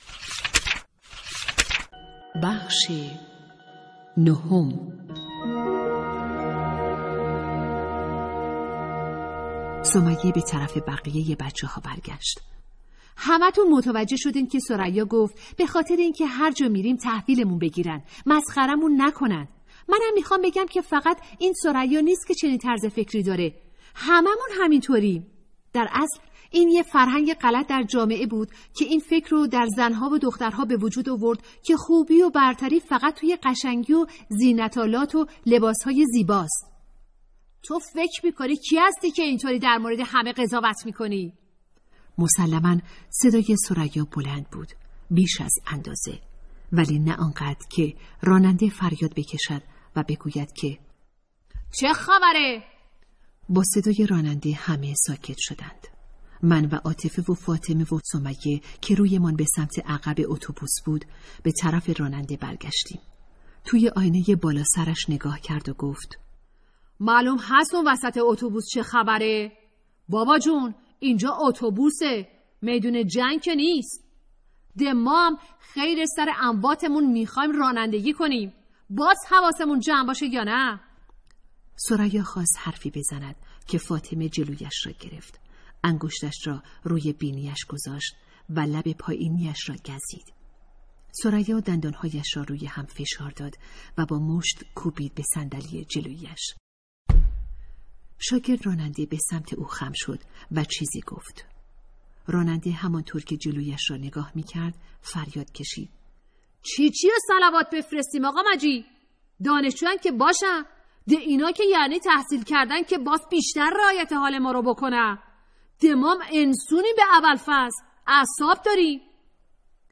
کتاب صوتی | دختران آفتاب (09)
کتاب صوتی دختران آفتابیک داستان بلند درباره دختران ایران